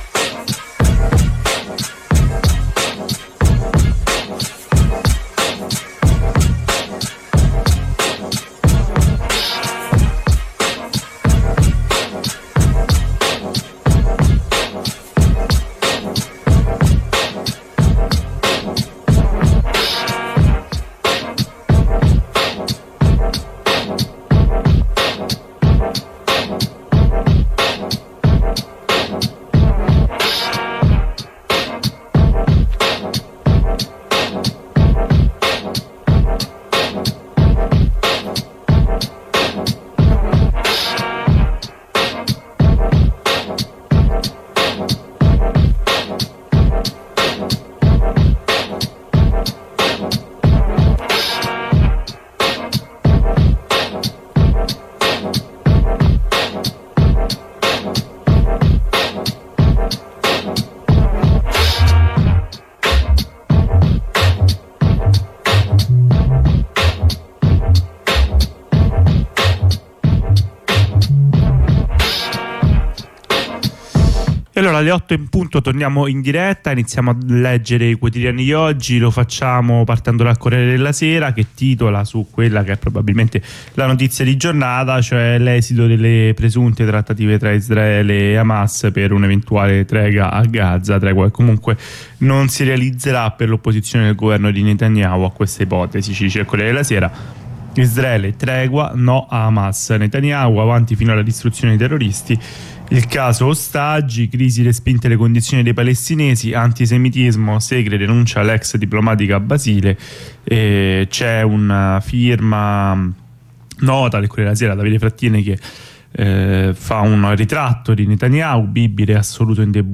la lettura dei quotidiani di oggi sugli 87.9 di radio ondarossa